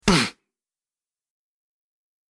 دانلود آهنگ تصادف 35 از افکت صوتی حمل و نقل
دانلود صدای تصادف 35 از ساعد نیوز با لینک مستقیم و کیفیت بالا
جلوه های صوتی